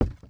Footstep_Wood 01.wav